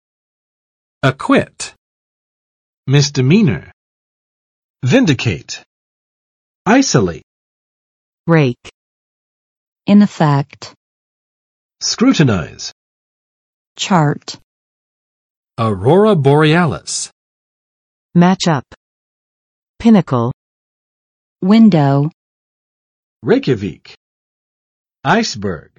[əˋkwɪt] v. 宣告……无罪，无罪释放